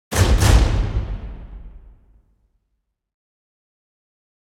Suspense 1 - Stinger 4.wav